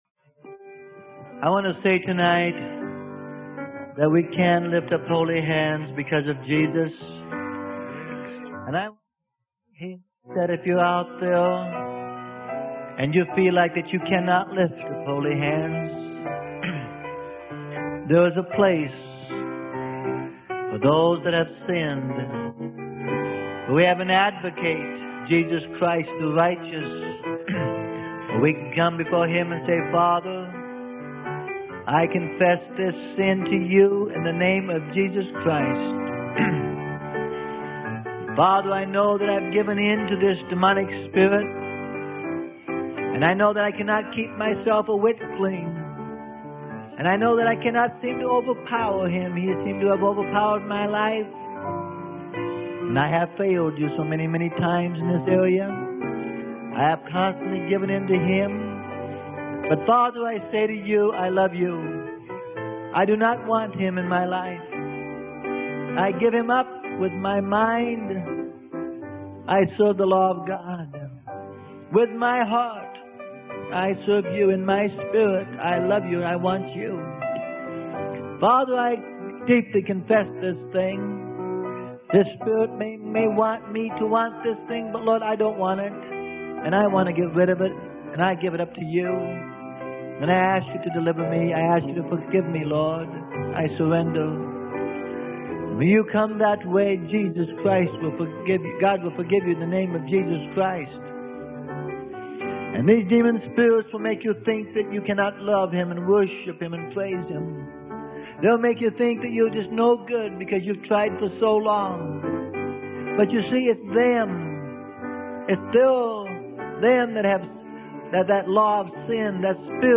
Sermon: Parallel Experiences in Demonic Deliverance - Tape 1 - Freely Given Online Library